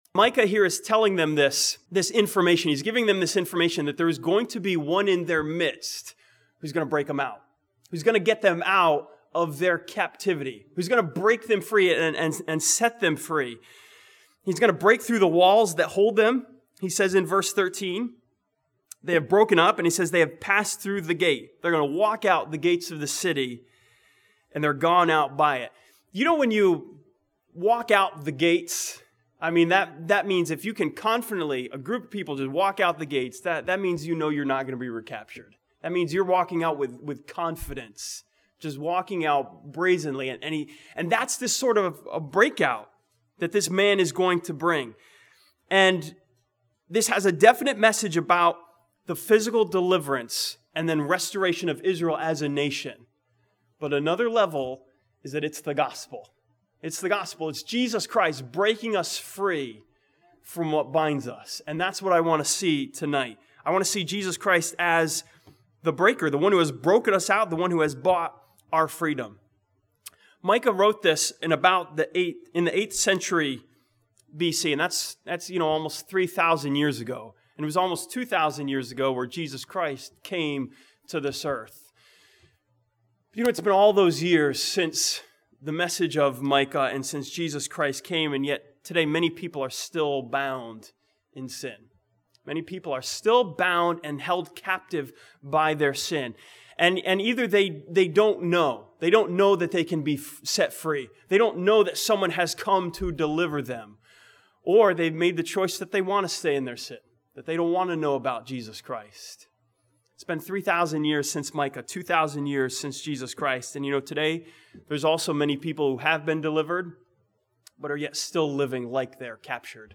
This sermon from Micah chapter 2 continues the series "Seeing Jesus" by seeing Him as the Breaker who leads us to freedom.